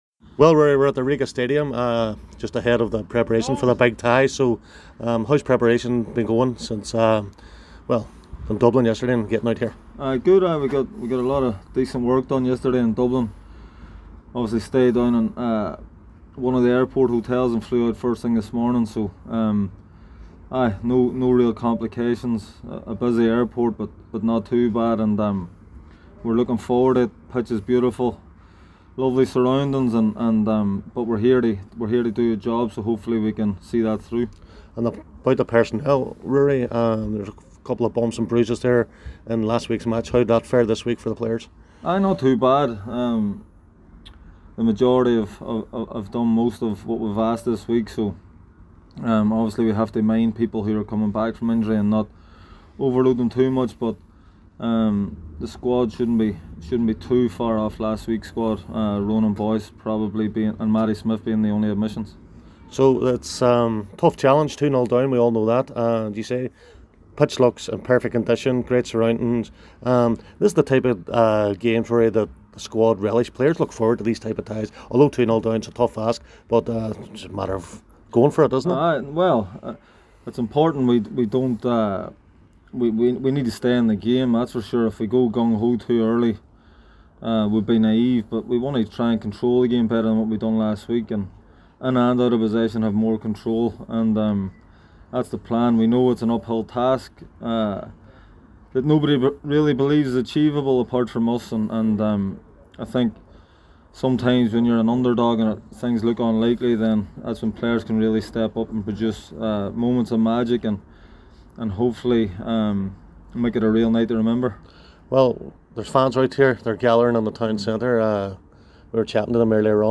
at the Skonto Stadium in Riga